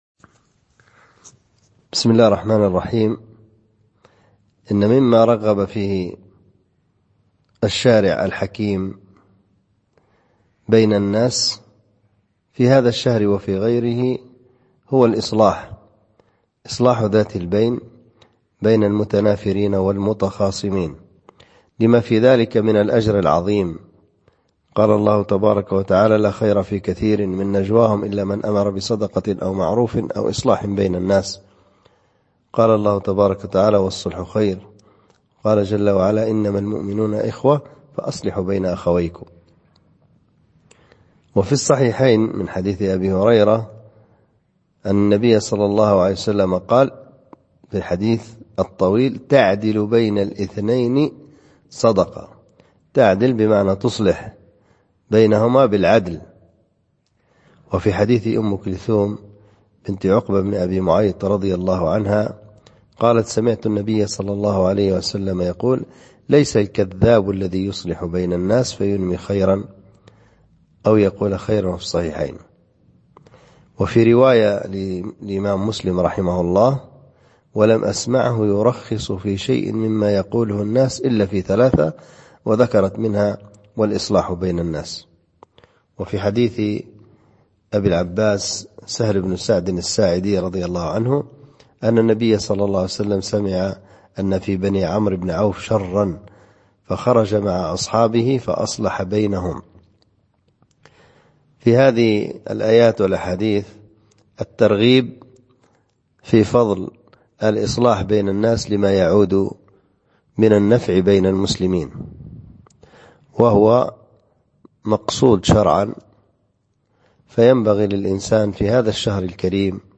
Format: MP3 Mono 22kHz 32Kbps (ABR)